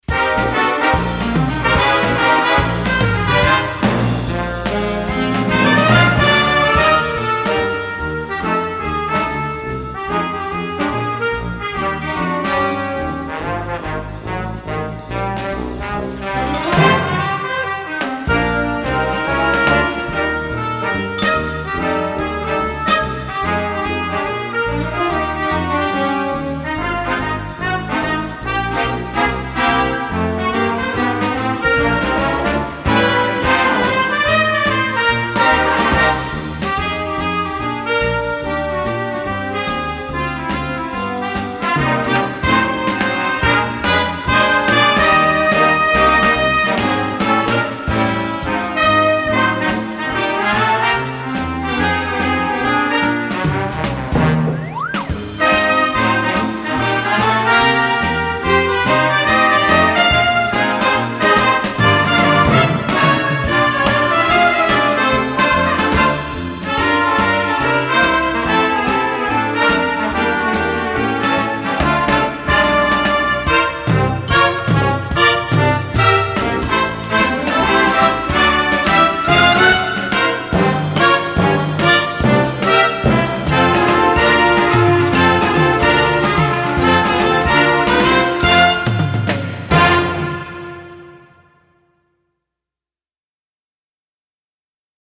Gattung: Flex Band (5-stimmig)
Besetzung: Blasorchester